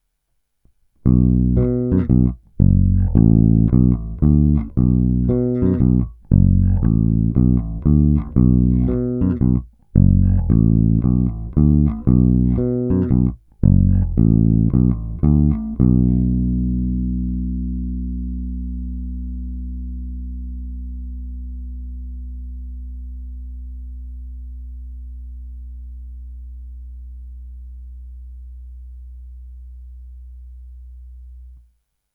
Následující nahrávky, není-li řečeno jinak, jsou provedeny rovnou do zvukové karty, jen normalizovány, jinak ponechány v původním stavu bez postprocesingu. Tónová clona byla vždy plně otevřená.
Oba snímače